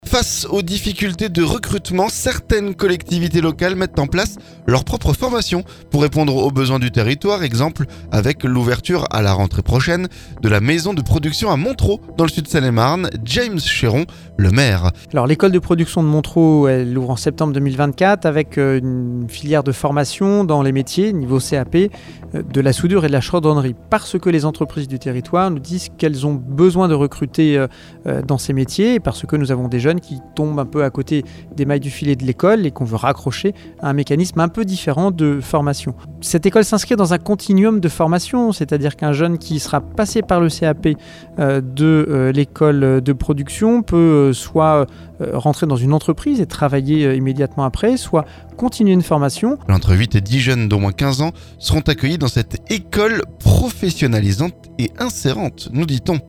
James Chéron, maire de la commune nous répond.